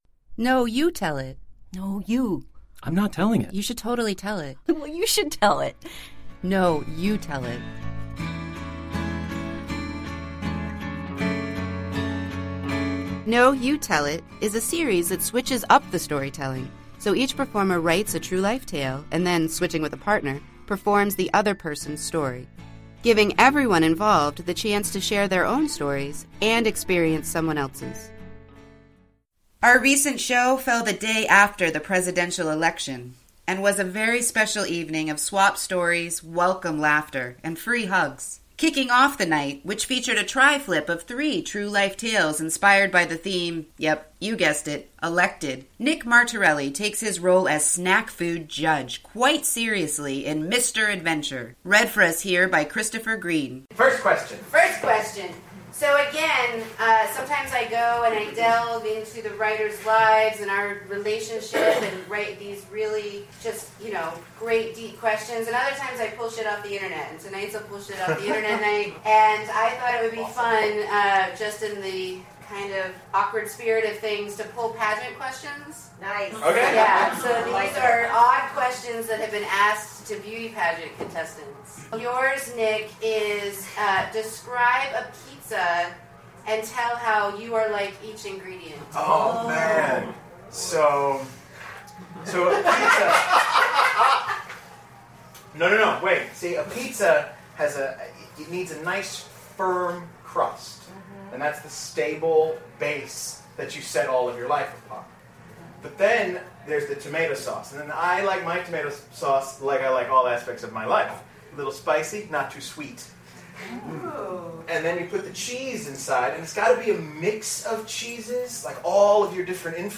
Our recent show fell the day after the presidential election and was a special evening of swapped stories, welcome laughter, and free hugs.